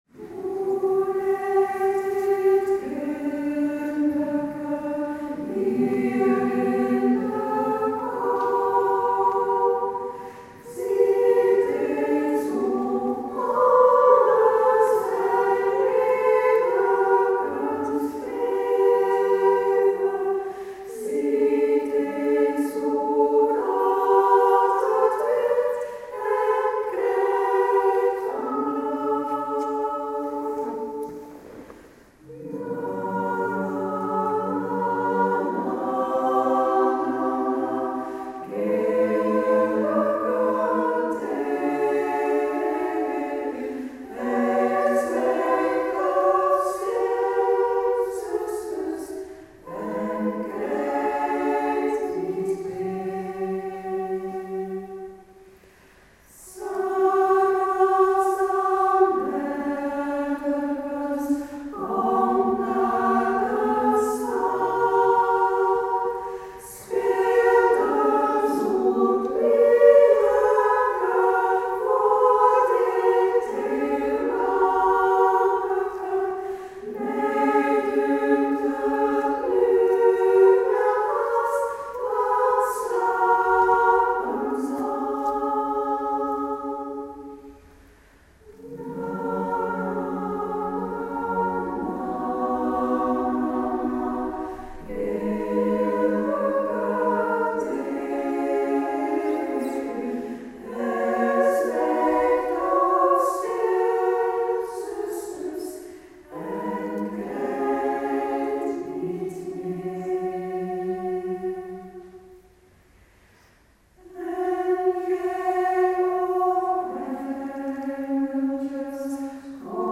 Kerstconcert
Piano en orgel